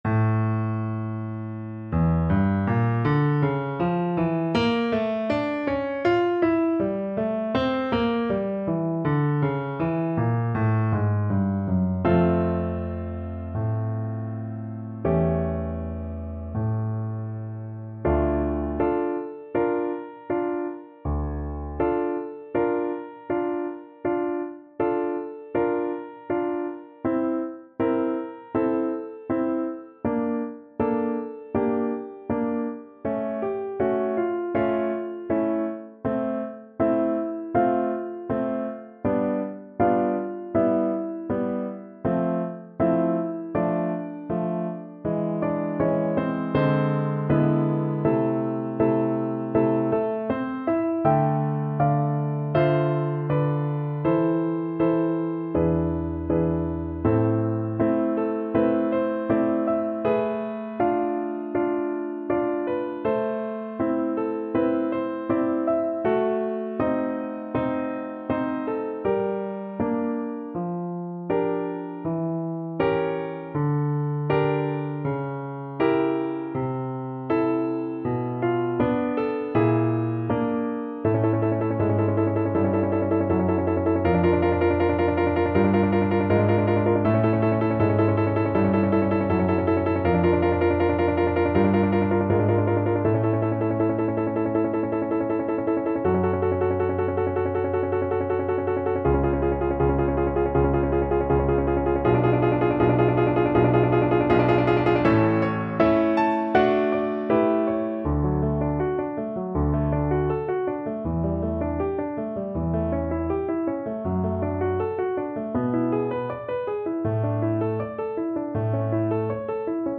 Play (or use space bar on your keyboard) Pause Music Playalong - Piano Accompaniment Playalong Band Accompaniment not yet available reset tempo print settings full screen
D minor (Sounding Pitch) (View more D minor Music for Viola )
~ = 100 Molto moderato =80
Classical (View more Classical Viola Music)